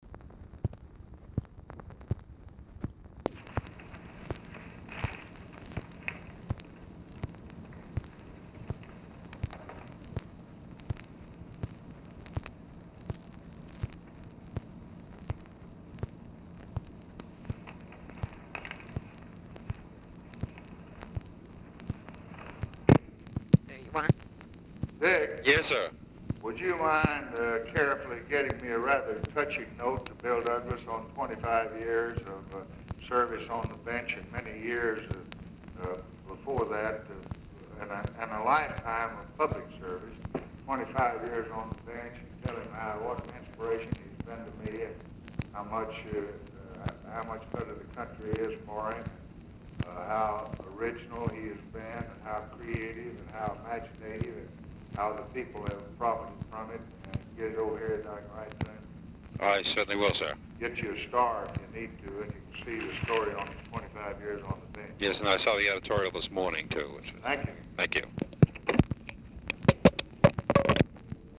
Telephone conversation # 3050, sound recording, LBJ and RICHARD GOODWIN, 4/17/1964, 12:00PM
Dictation belt
Oval Office or unknown location